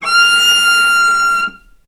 vc-F6-ff.AIF